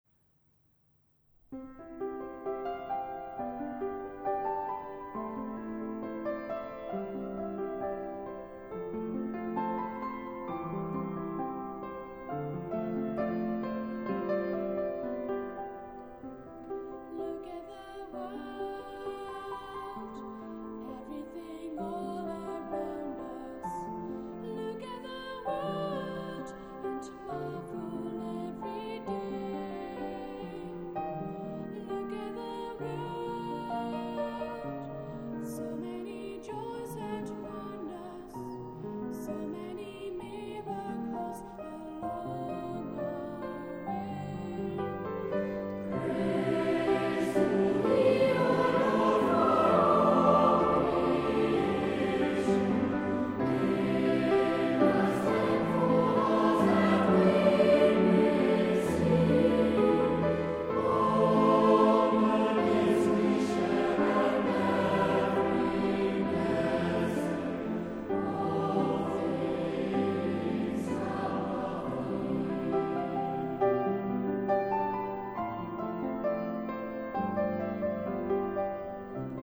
orgel en piano